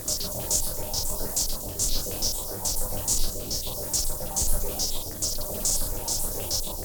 STK_MovingNoiseA-140_02.wav